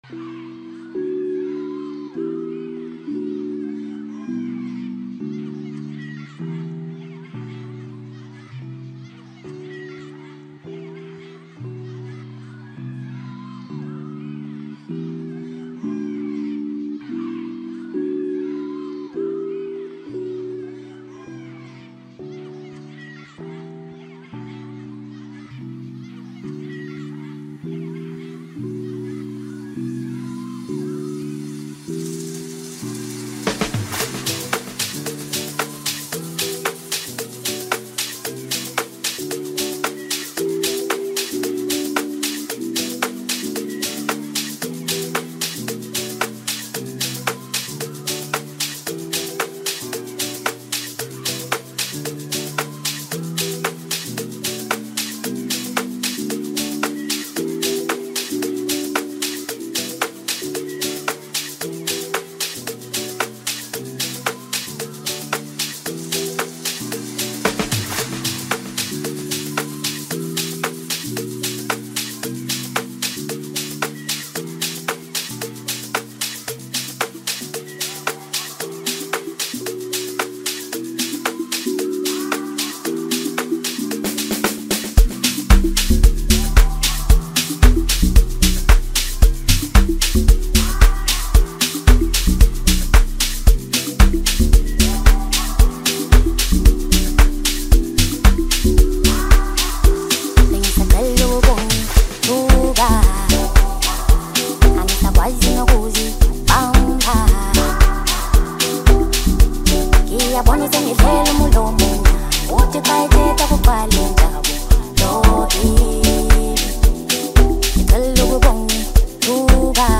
strong vocals, emotional depth